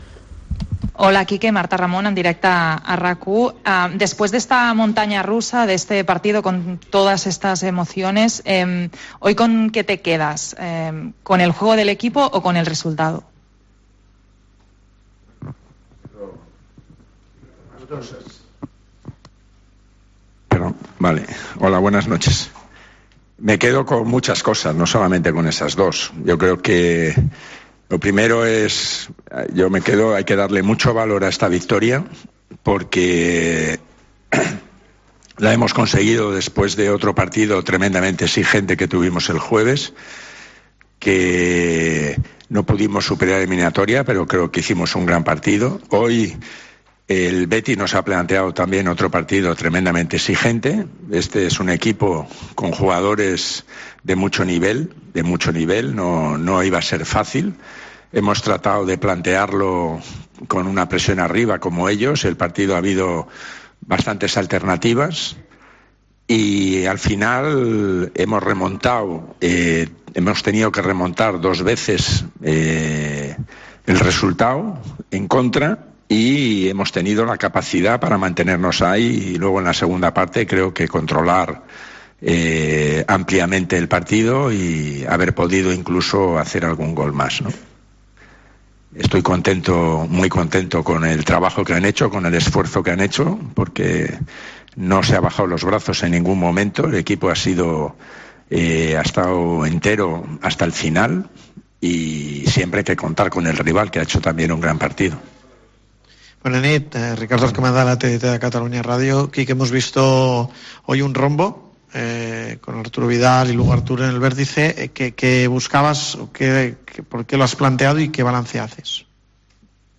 AUDIO: Escucha la rueda de prensa del entrenador del FC Barcelona tras la victoria contra el Betis por 2-3